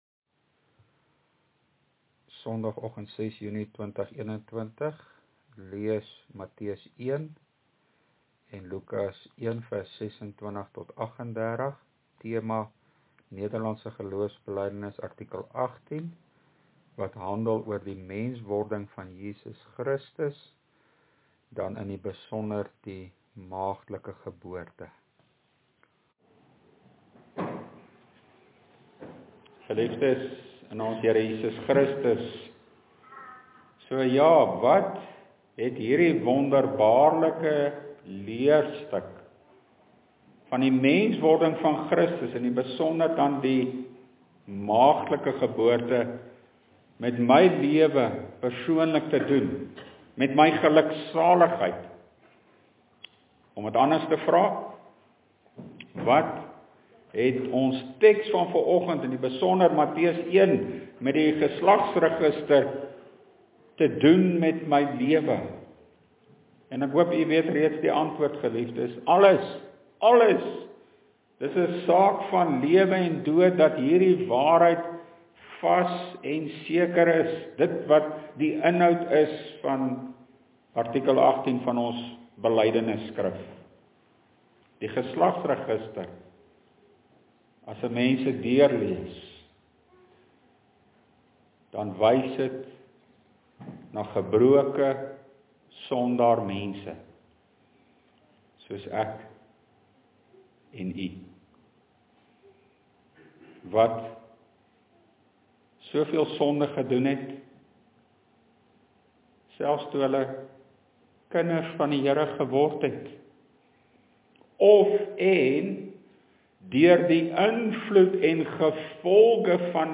LEERPREDIKING: NGB artikel 18